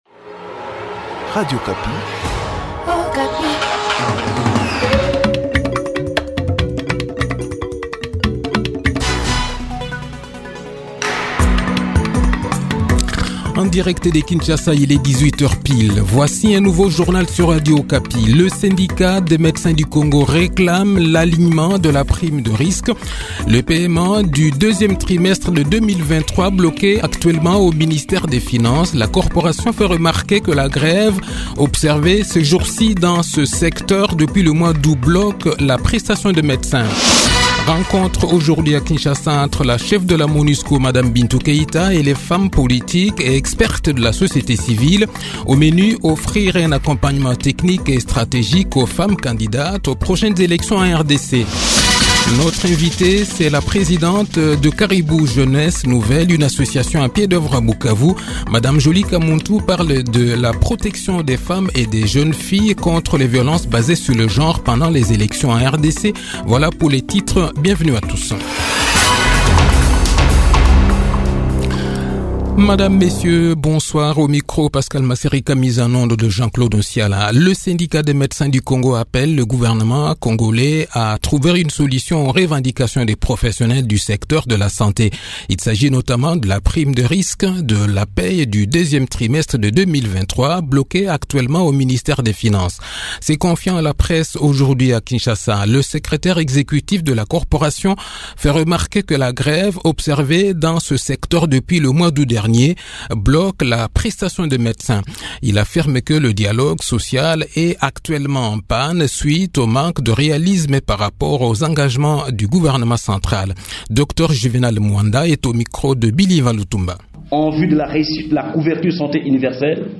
Le journal de 18 h, 19 septembre 2023